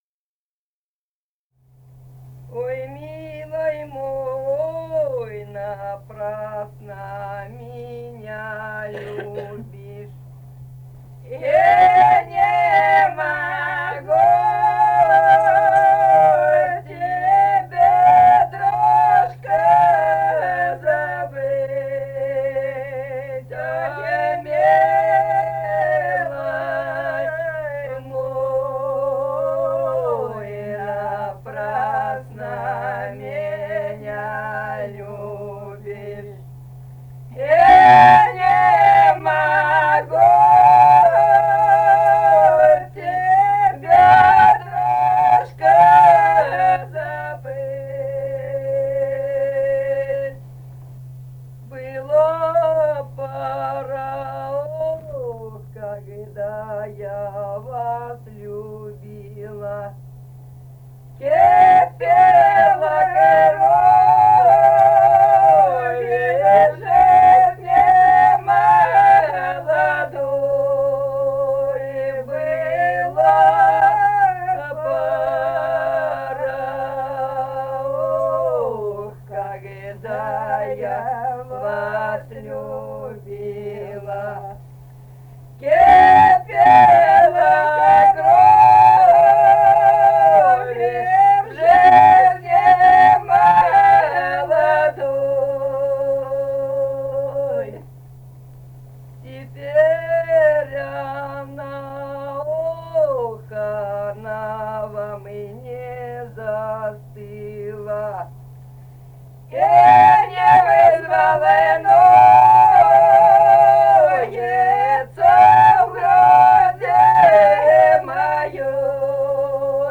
Этномузыкологические исследования и полевые материалы
Самарская область, с. Усманка Борского района, 1972 г. И1317